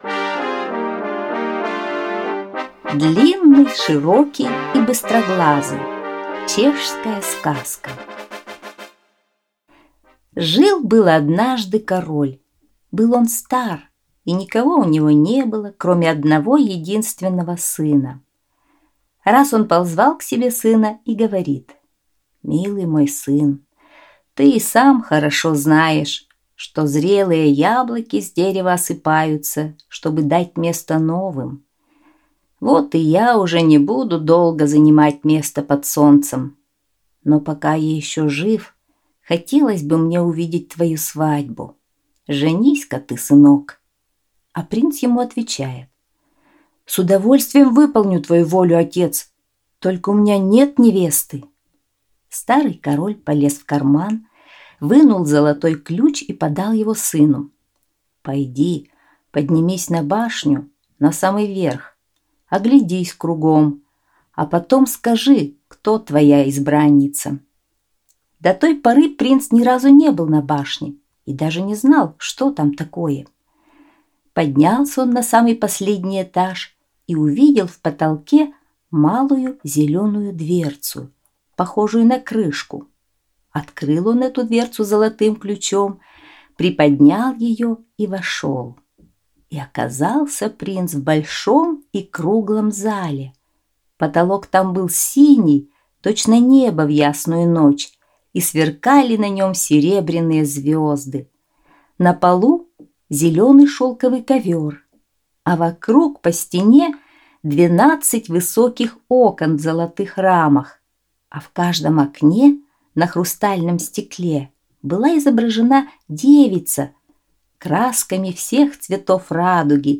Длинный, Широкий и Быстроглазый - чешская аудиосказка